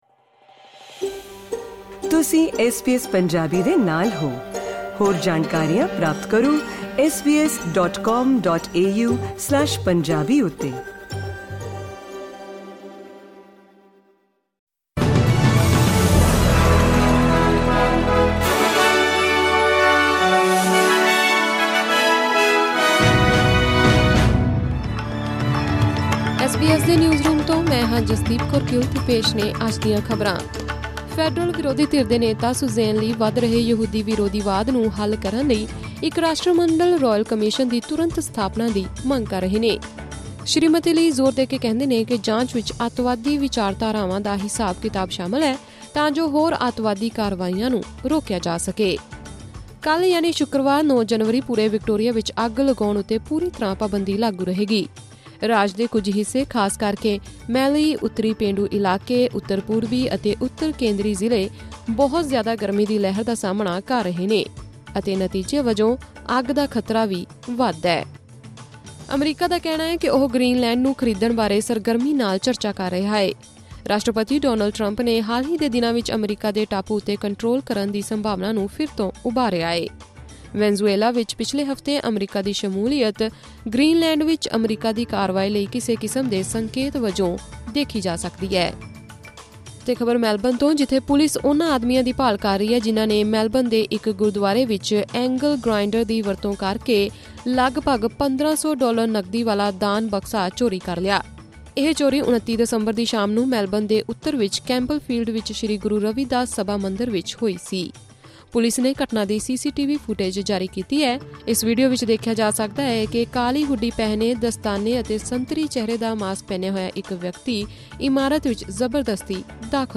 ਖ਼ਬਰਨਾਮਾ: ਵੈਨੇਜ਼ੁਏਲਾ 'ਚ ਕਾਰਵਾਈ ਤੋਂ ਬਾਅਦ ਹੁਣ ਗ੍ਰੀਨਲੈਂਡ ਤੇ ਕਬਜ਼ੇ ਦੀ ਤਿਆਰੀ 'ਚ ਅਮਰੀਕਾ